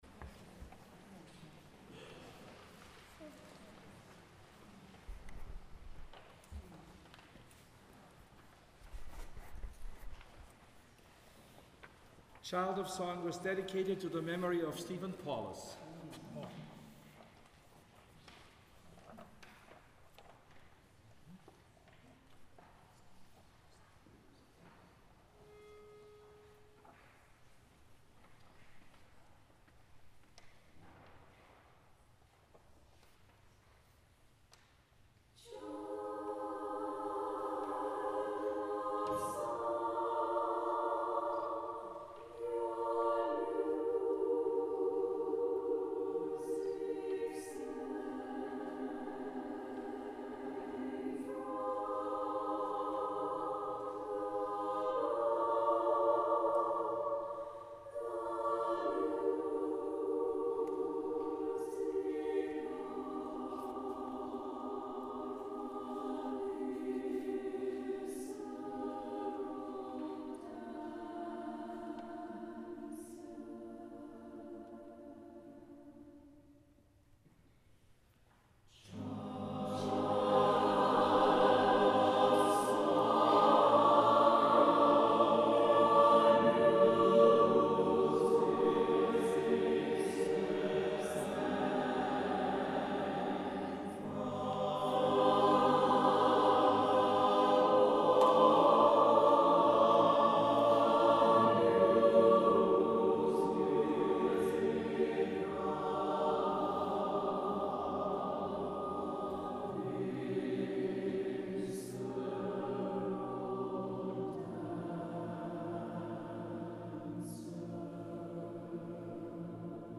Kantorei Spring concert